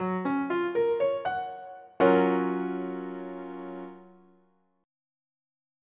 MIDI Music File
_F#MAJ7.mp3